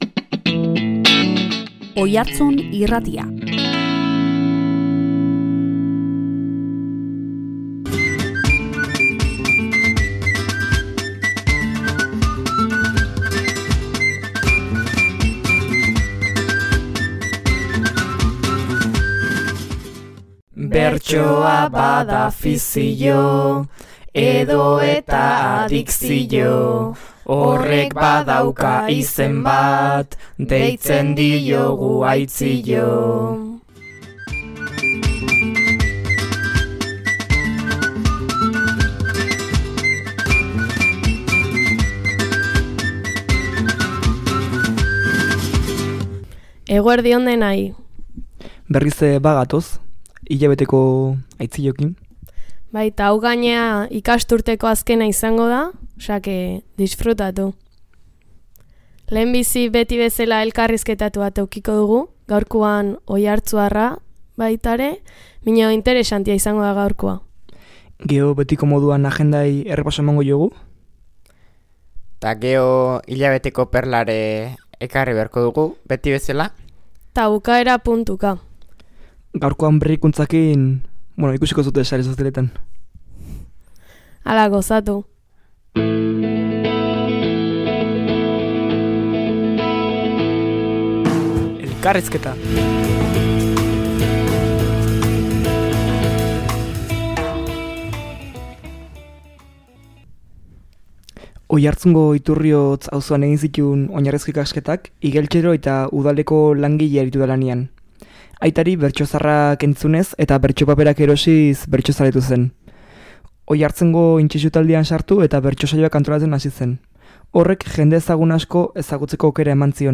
Ohi bezala hilabete perla (AmetsArzallusek idatzitako bertsoak), agenda eta irratsaioaren laburpena koplaka egin dute. Irailean Oiartzun Irratian bertsolaritza lantzen segiko dutela aipatu dute gazteek.